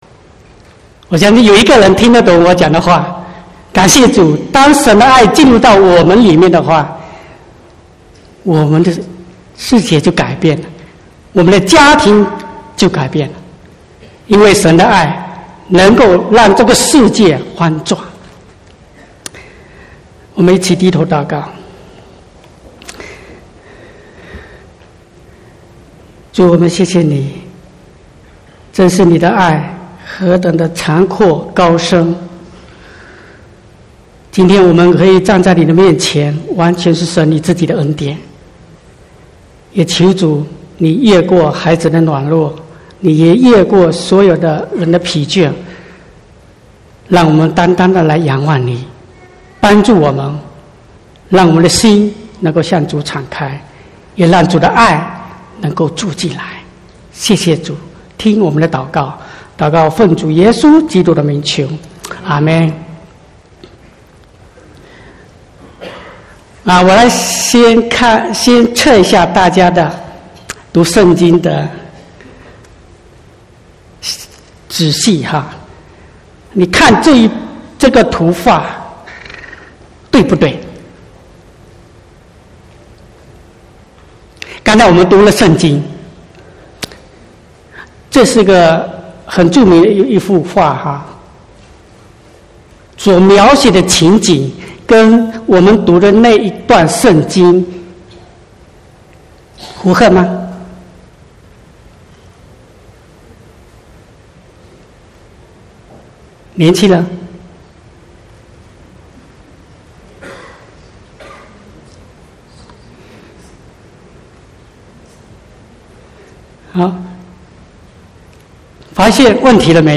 24/3/2019 國語堂講道